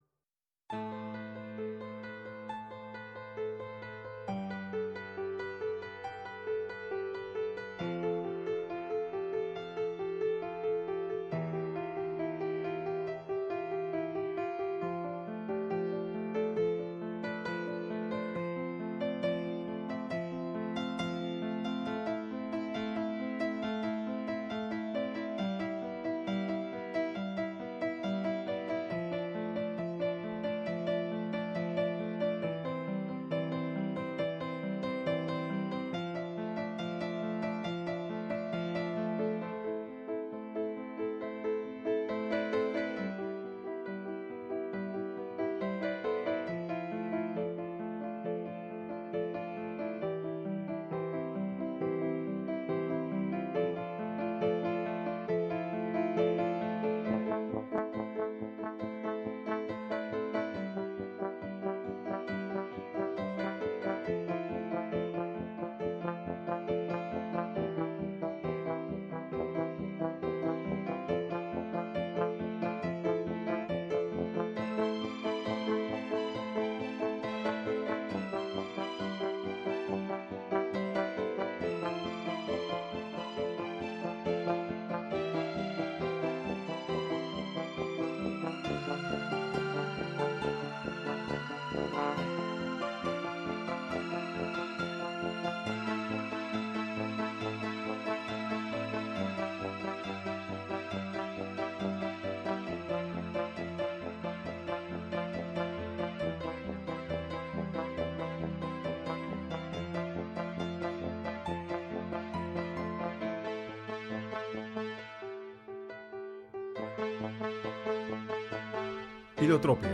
Ποιήματα του Παλαμά, του Δροσίνη, του Άγρα, του Σπεράντζα, του Χατζόπουλου, του Θέμελη κ.ά. Η εκπομπή διανθίστηκε με αγαπημένα Χριστουγεννιάτικα τραγούδια